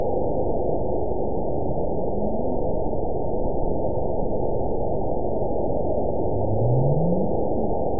event 911748 date 03/07/22 time 15:58:57 GMT (3 years, 3 months ago) score 9.70 location TSS-AB01 detected by nrw target species NRW annotations +NRW Spectrogram: Frequency (kHz) vs. Time (s) audio not available .wav